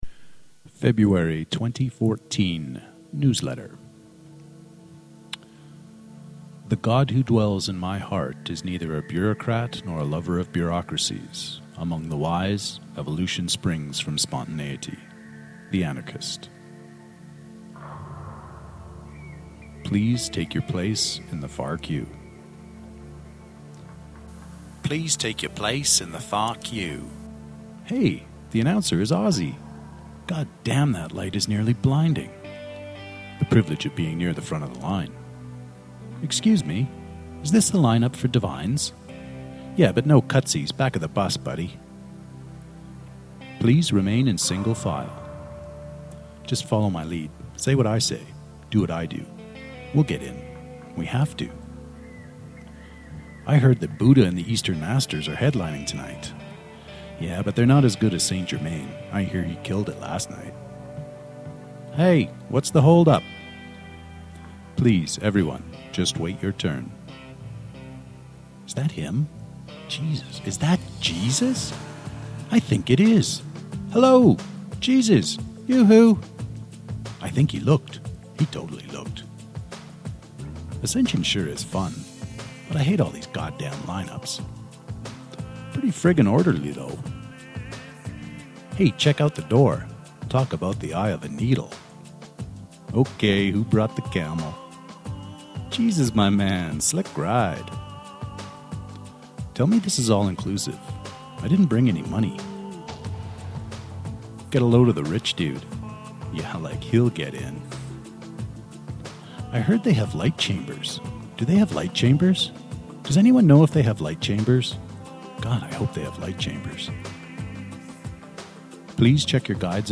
(Author Narration with musical accompaniment: I think Lesiem, but I’m not too sure.)